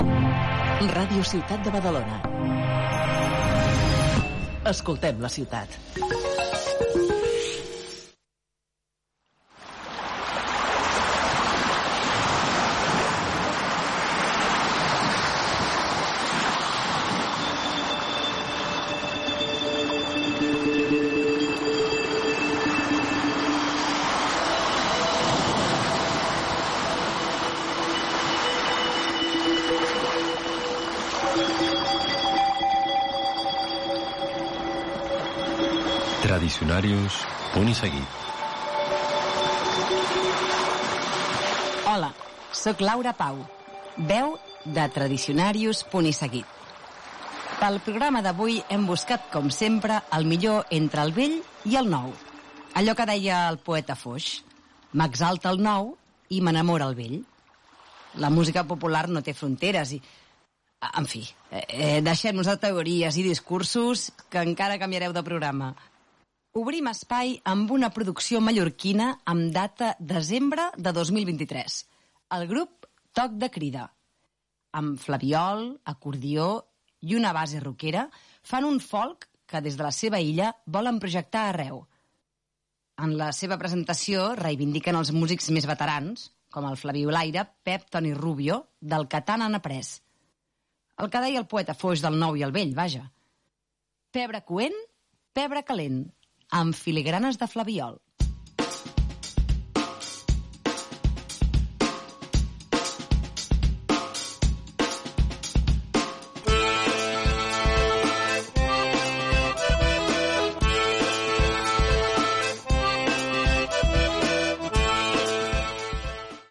Indicatiu de l'emissora, careta del programa, inici de l'espai dedicat a la música popular amb la presentació d'un tema musical publicat aquell any
Musical